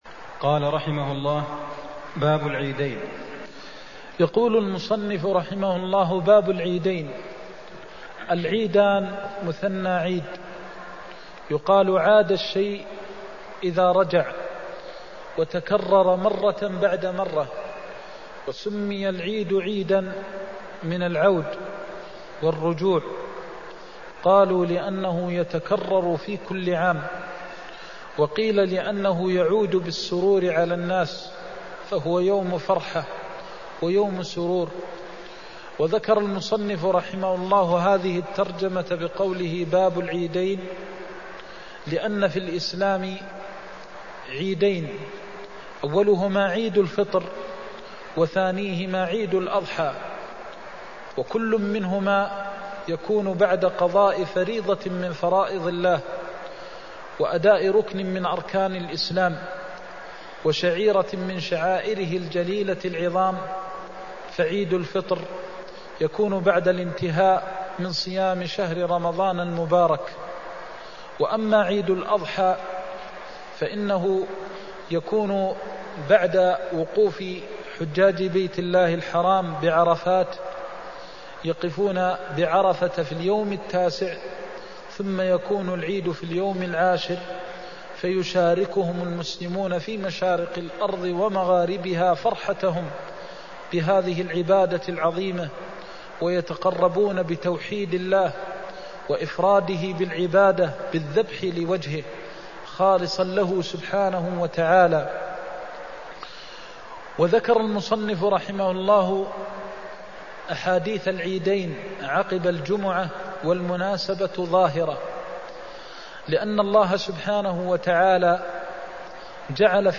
المكان: المسجد النبوي الشيخ: فضيلة الشيخ د. محمد بن محمد المختار فضيلة الشيخ د. محمد بن محمد المختار وقت صلاة العيد (136)